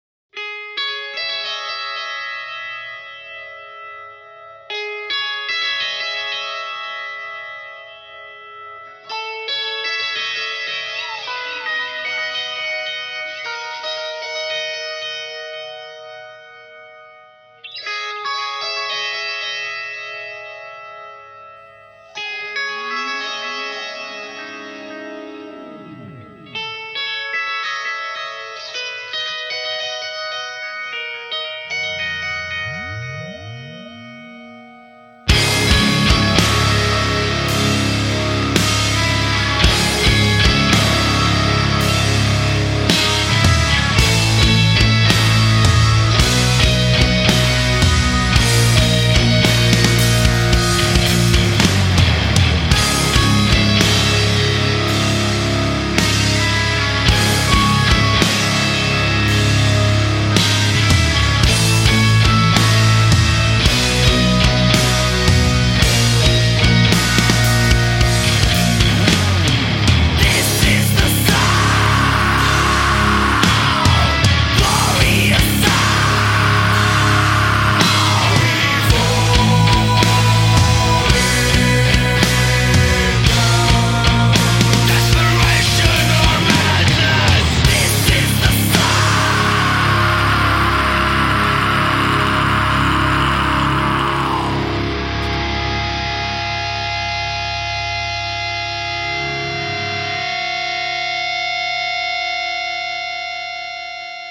Žánr: Metal/HC